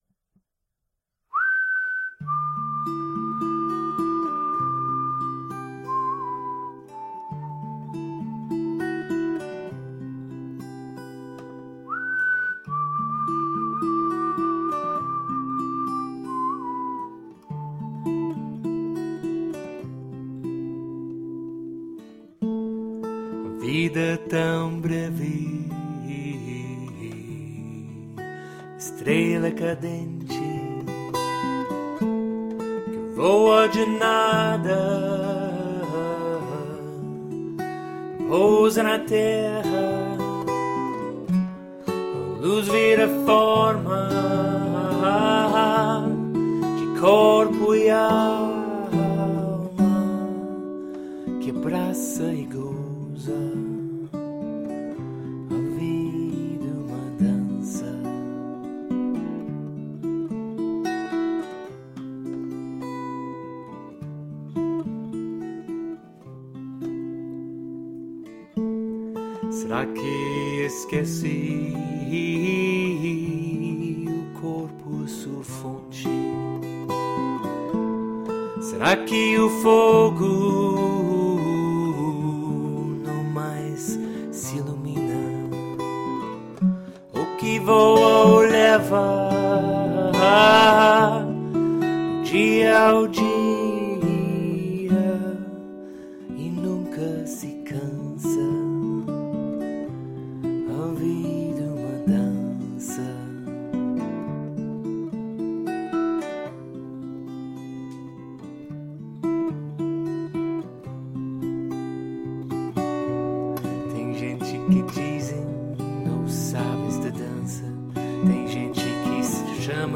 And as that phrase repeated itself within me, I found there was music behind it, and with music — with that vibration which runs through all things — the sounds led to another line, and the next line to more song, and this bosso nova melody and words came to me about our place in Light.